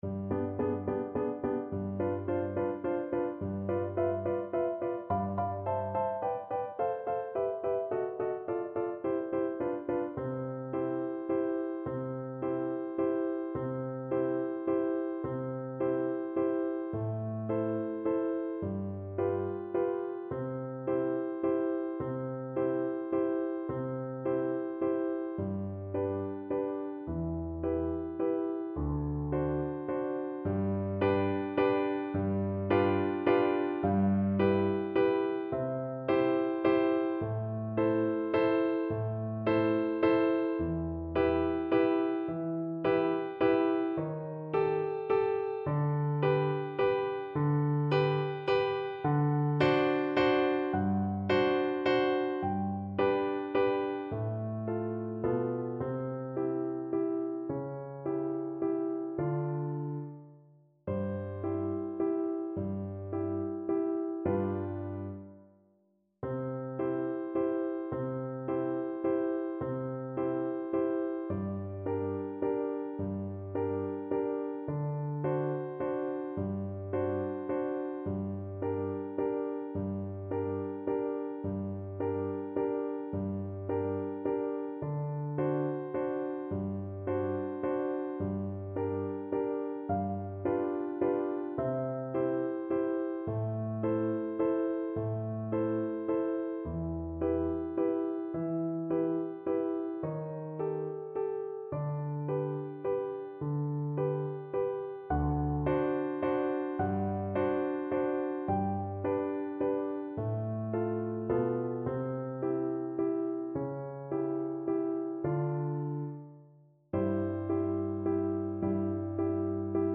Classical Verdi, Giuseppe Ella mi fu rapita from Rigoletto Flute version
Play (or use space bar on your keyboard) Pause Music Playalong - Piano Accompaniment Playalong Band Accompaniment not yet available transpose reset tempo print settings full screen
Flute
C major (Sounding Pitch) (View more C major Music for Flute )
3/4 (View more 3/4 Music)
Adagio =50
Classical (View more Classical Flute Music)
verdi_ella_mi_fu_rapita_FL_kar3.mp3